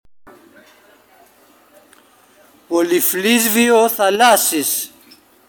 Ainsi, πολυφλοίσβοιο θαλάσσης, le son du ressac en grec homérique, se prononcerait bien différemment aujourd’hui :